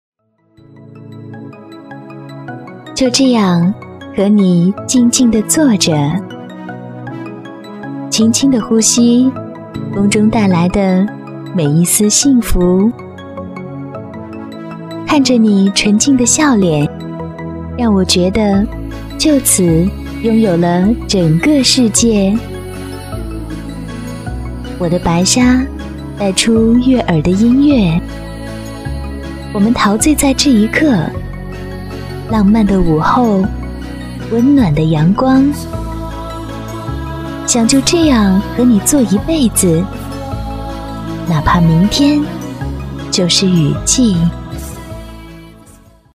促销广告